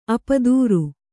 ♪ apadūru